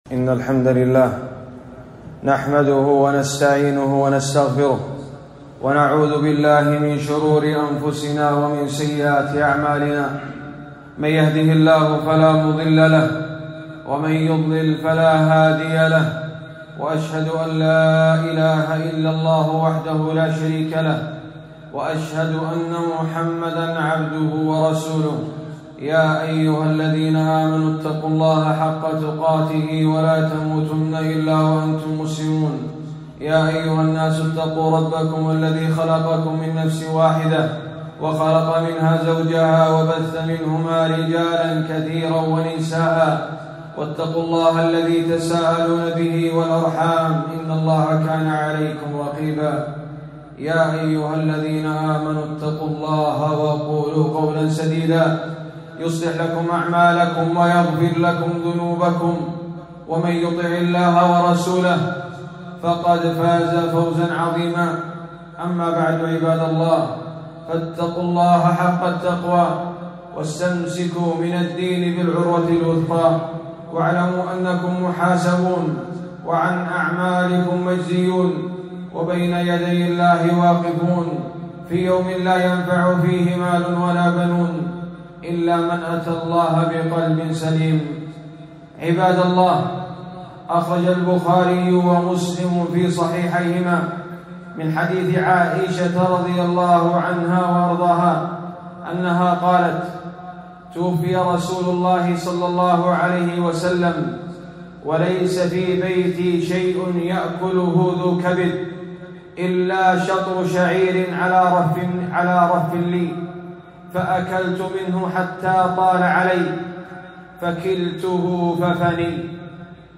خطبة - إحصاء المال ذهاب لبركته